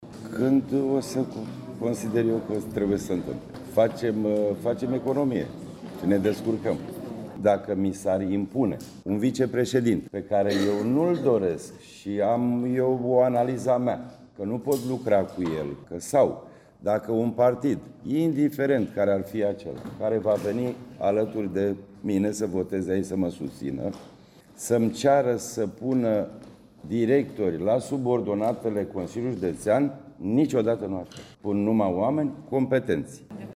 Întrebat când va fi ales al doilea vice, președintele CJC, Florin Mitroi, a declarat că încă se poartă discuții: